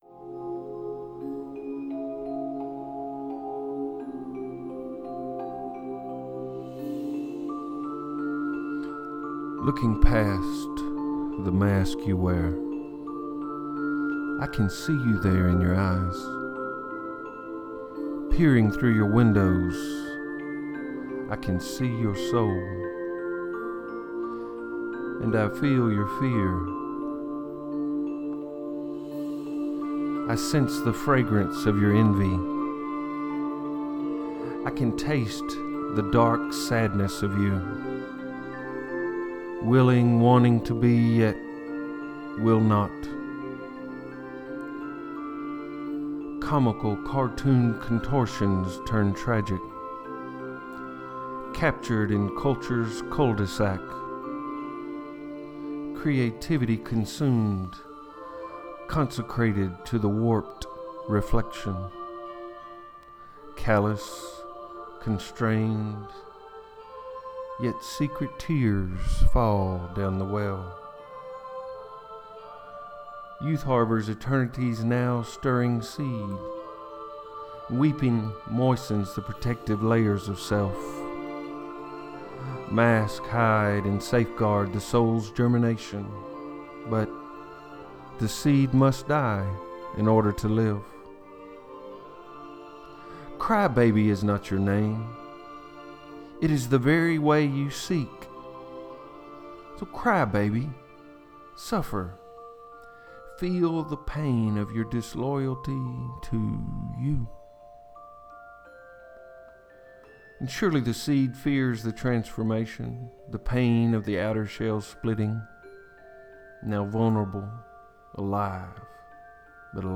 Great reading voice.